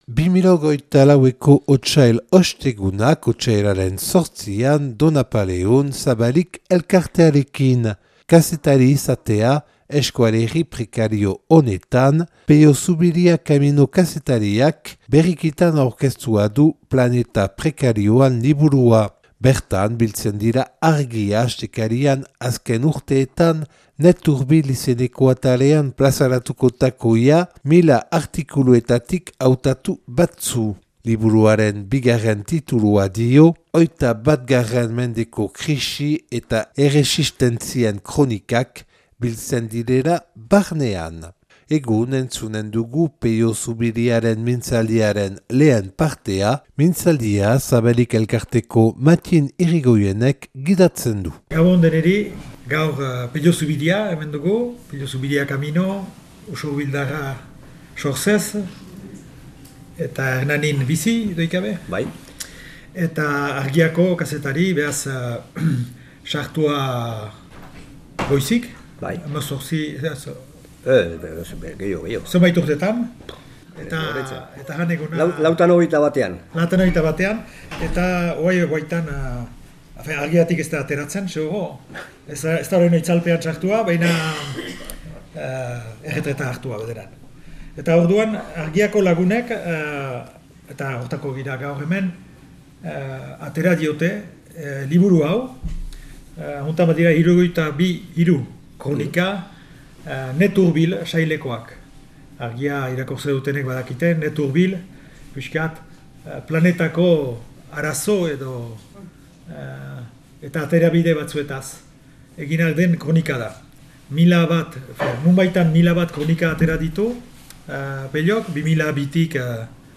(Donapaleu grabatua 2024. otsailaren 8an « Otsail Ostegunak » Zabalik elkarteak antolaturik.